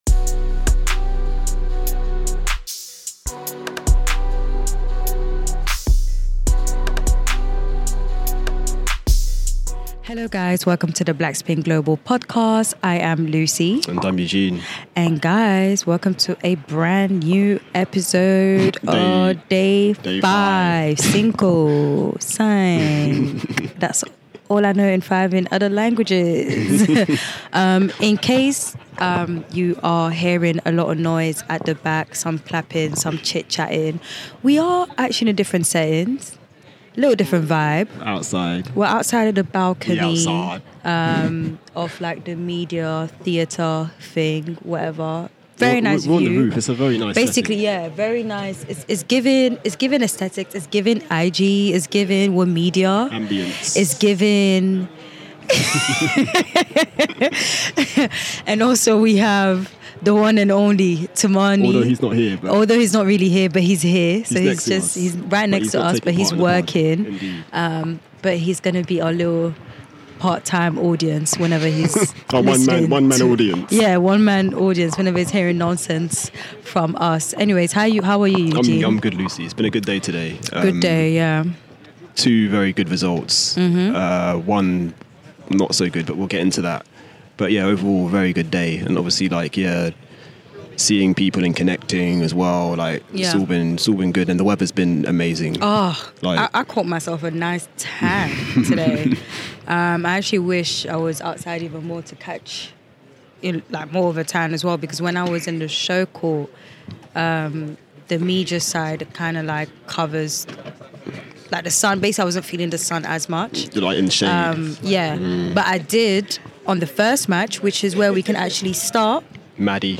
Interview snippets from Keys’ and Eubanks’ pressers included.